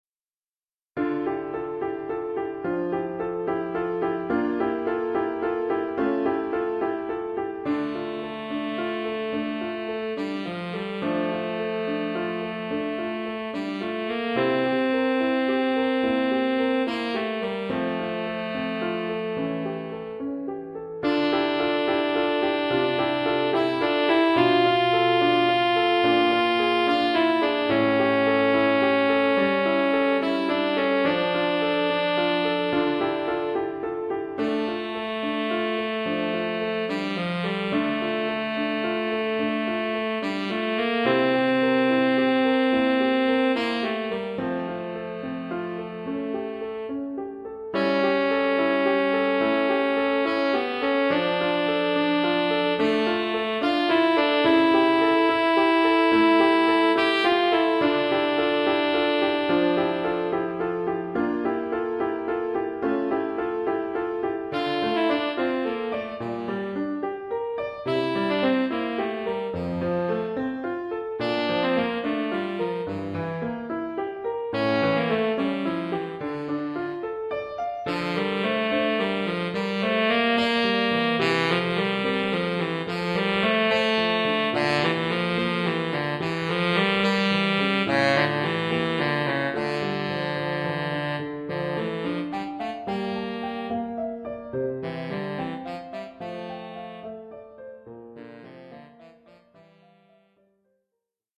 Formule instrumentale : Saxophone sib et piano
Oeuvre pour saxophone sib et piano.